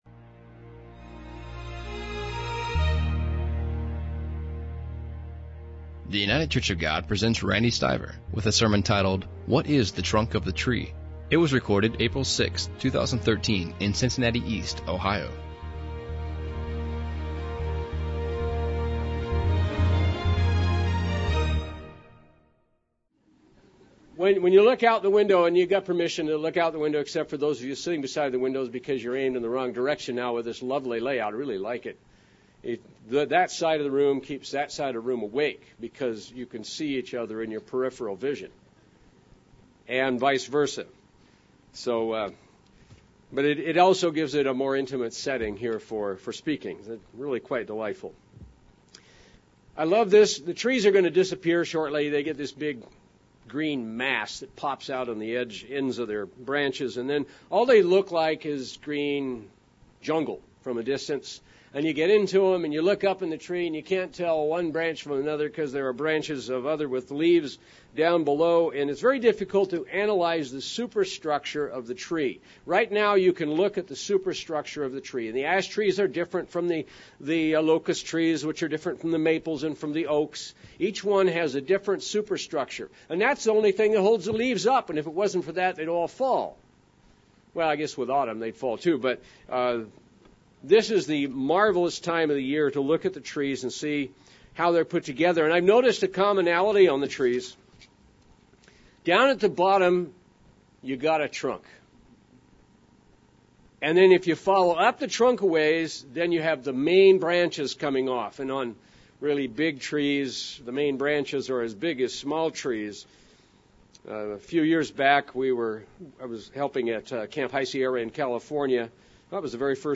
And, granted, there are branches that branch off of the main trunk, and we'll come to see that toward the end of the sermon.